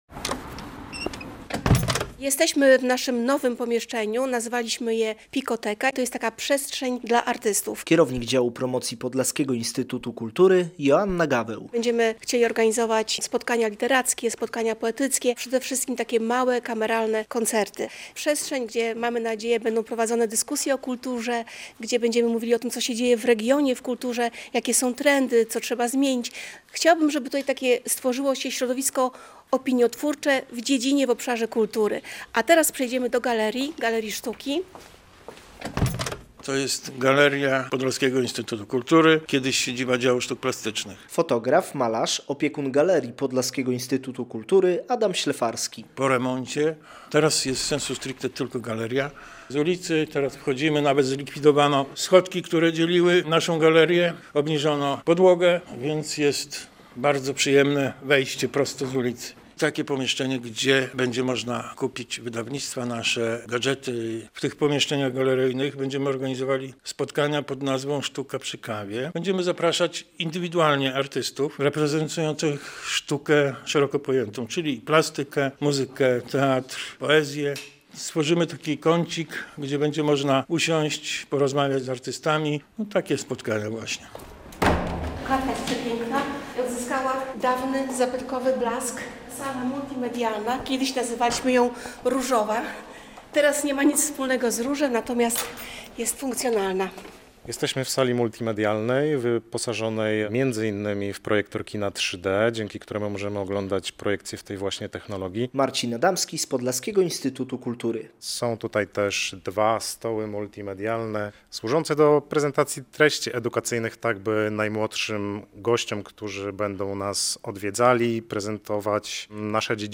Przy ul. Kilińskiego 8 jest galeria, pokoje pracy twórczej, nowoczesna sala multimedialna i PiKoteka - relacja